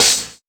• Urban Open Hat One Shot F# Key 04.wav
Royality free open hi hat sample tuned to the F# note. Loudest frequency: 5789Hz
urban-open-hat-one-shot-f-sharp-key-04-tjm.wav